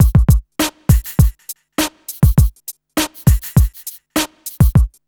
HF101BEAT4-R.wav